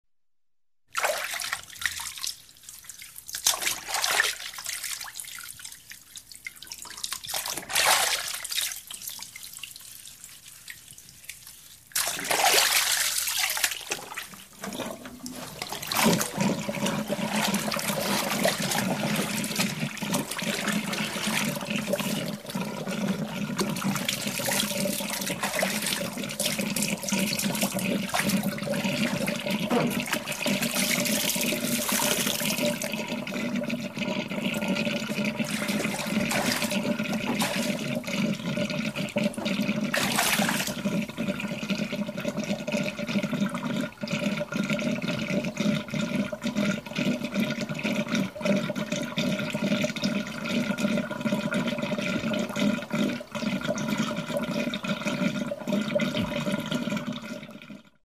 Звуки купания в ванной хлюпанье плеск хрип слива воды